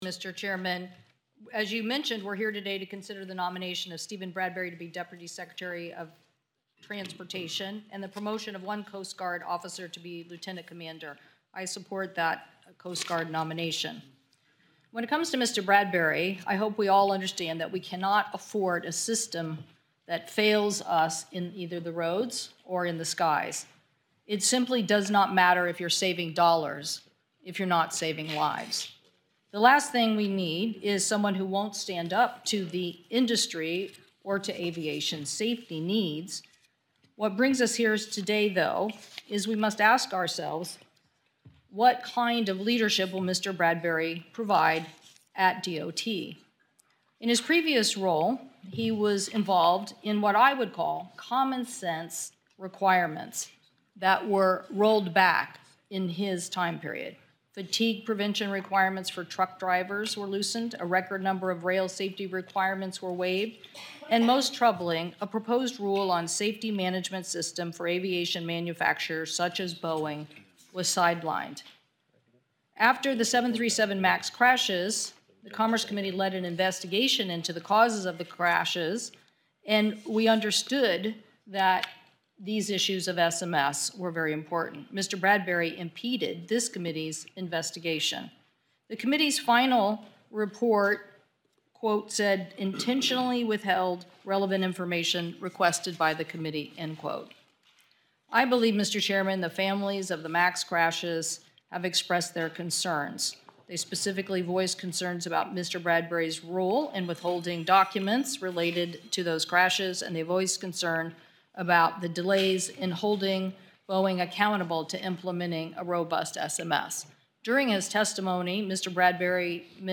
WASHINGTON, D.C. – U.S. Senator Maria Cantwell, Ranking Member of the Committee on Commerce, Science and Transportation, delivered the below remarks before she, and all Democrats on the committee, voted against advancing Steven Bradbury, President Trump’s nominee for Deputy Secretary at the Department of Transportation.